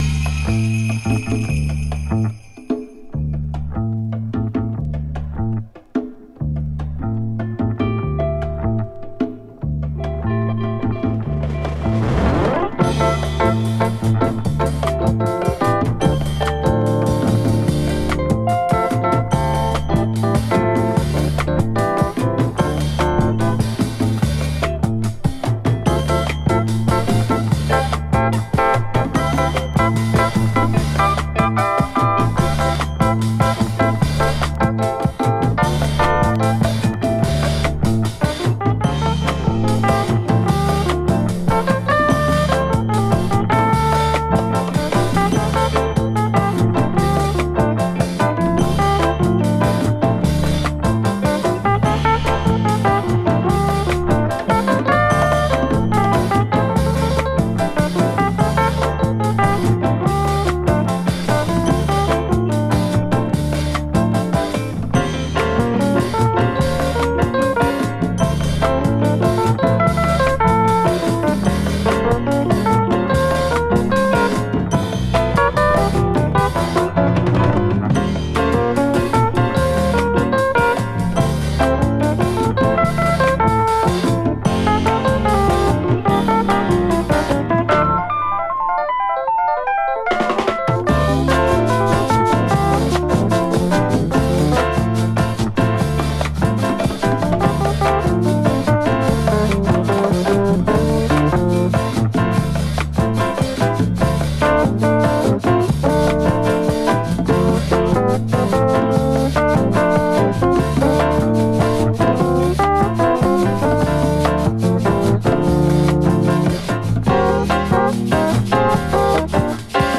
> JAZZ FUNK/RARE GROOVE
パーカッシヴなビートとエレピのエモーショナルな旋律との絡み合いが心地よいグルーヴィ・ジャズ